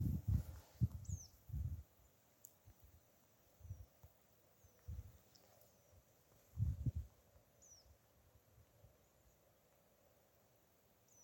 Птицы -> Коньки -> 3
краснозобый конек, Anthus cervinus
СтатусСлышен голос, крики